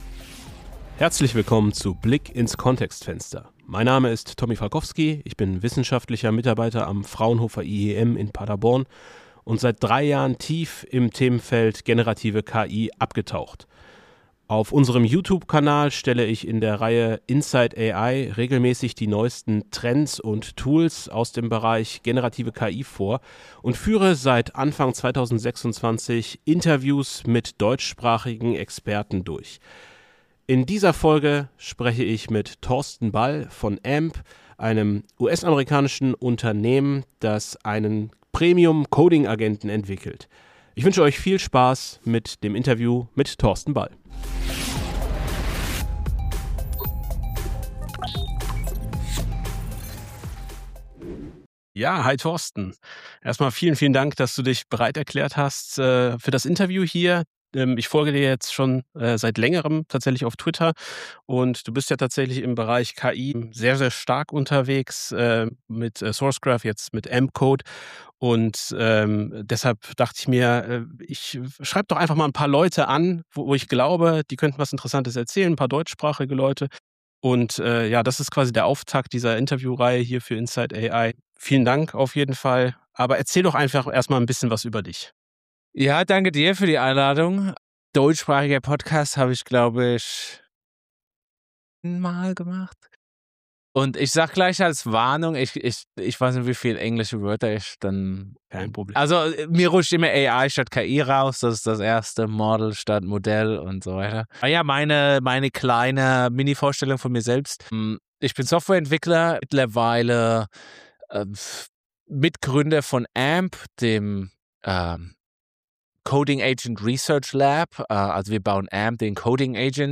Beschreibung vor 4 Wochen Mit dieser Folge startet Inside AI eine neue Interviewreihe mit spannenden Gästen aus der KI-Welt.